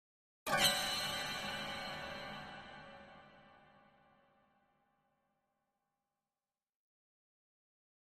Harmonic Plucks Sharp Horror Chord 3